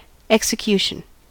execution: Wikimedia Commons US English Pronunciations
En-us-execution.WAV